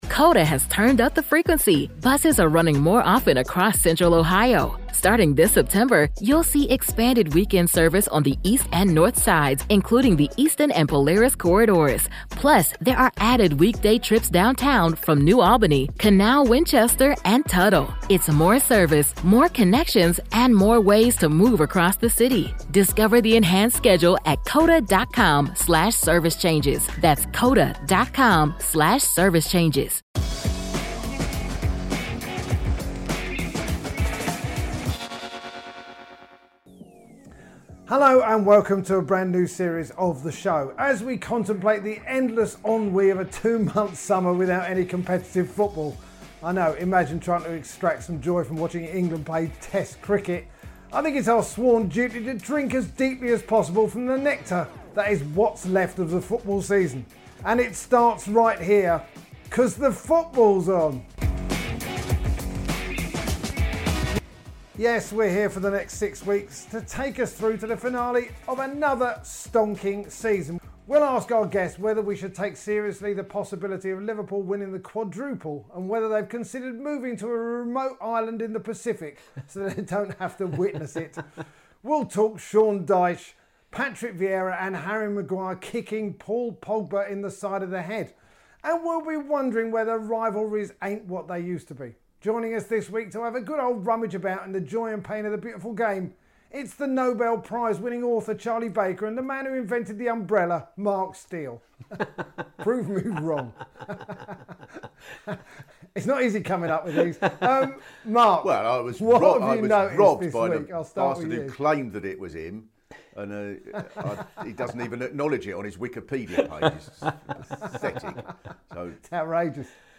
In this week's show, host Ian Stone is joined by the right honourable Mark Steel and Charlie Baker to reflect on all the latest action.